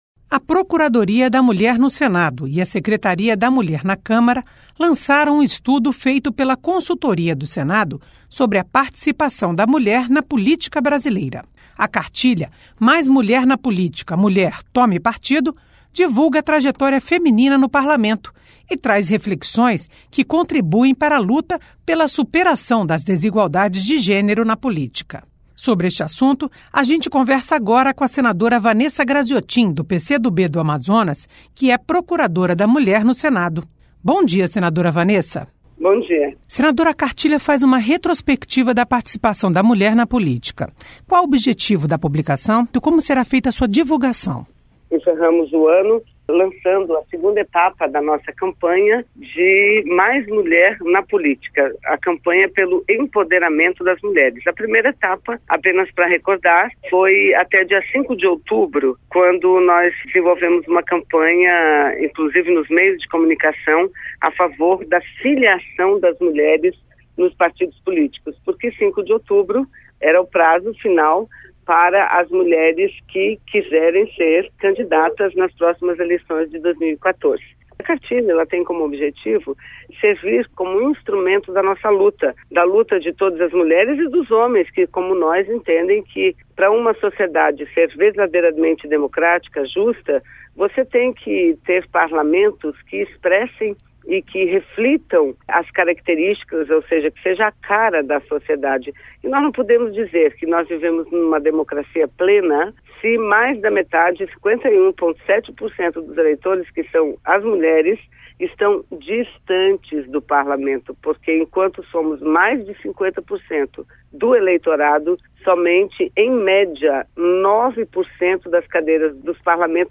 Entrevista: Cartilha incentiva participação feminina na política
Entrevista: Cartilha incentiva participação feminina na política Entrevista com a senadora Vanessa Grazziotin (PCdoB-AM).